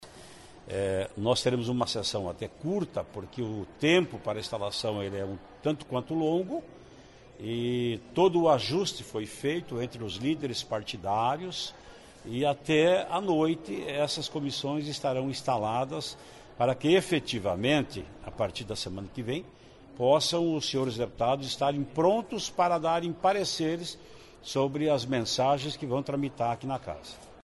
Ele conversou com os jornalistas antes de começar a sessão desta terça-feira (19).